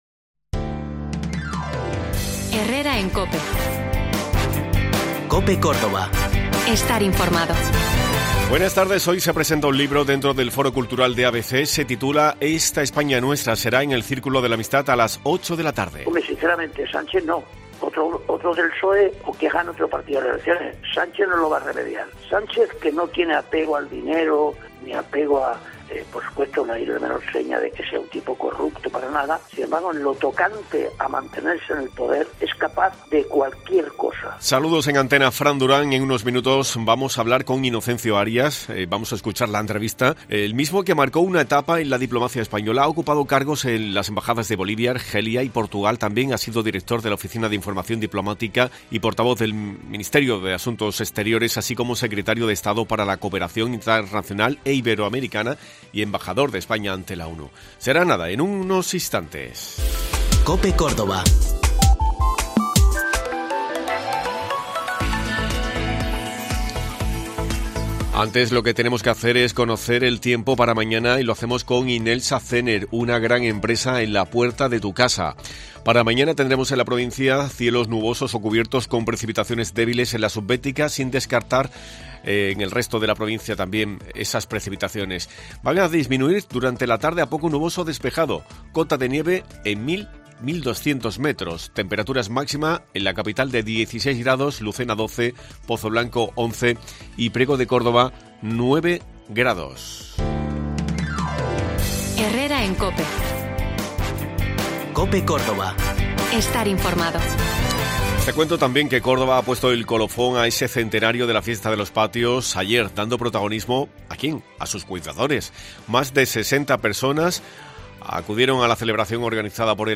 Hoy Inocencio Arias presenta su nuevo libro "Esta España nuestra" y hemos hablado con él. Arias marcó una etapa en la diplomacia española.